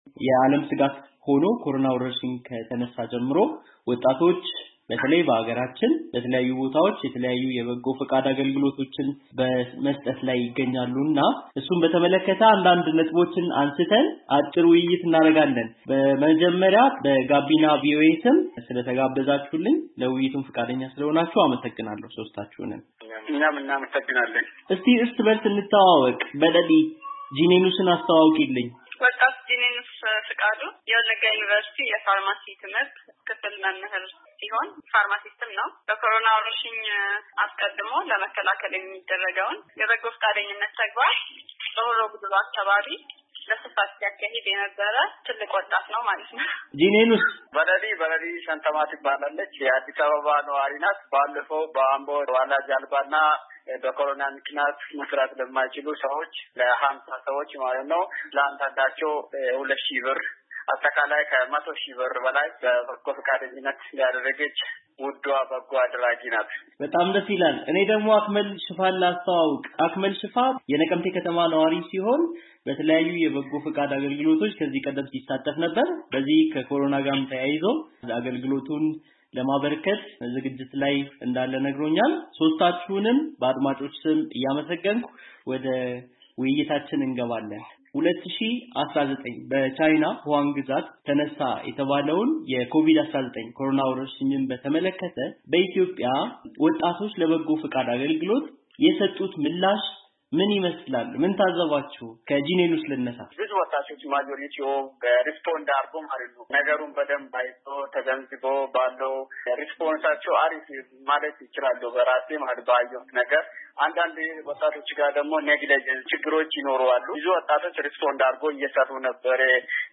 ውይይት | የኮቪድ19 ስርጭትን ለመግታት የበጎ-ፈቃደኞች ሚና ሲመዘን